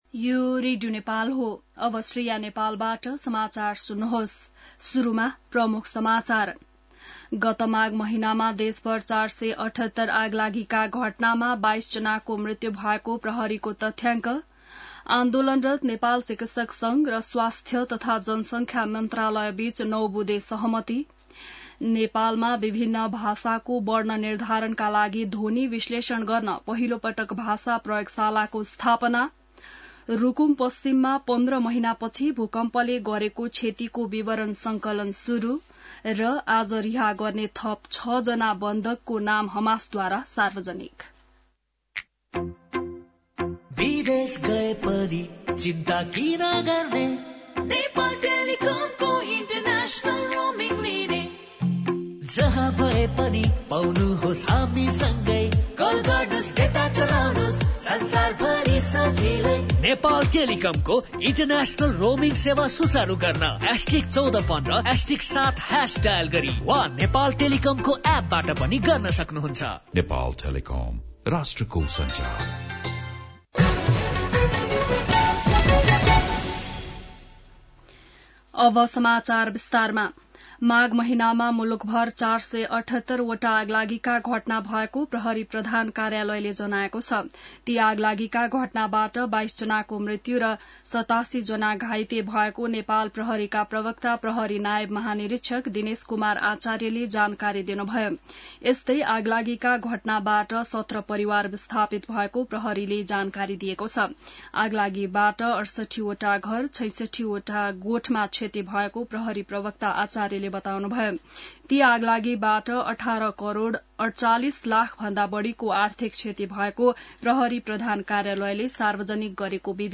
बिहान ७ बजेको नेपाली समाचार : ११ फागुन , २०८१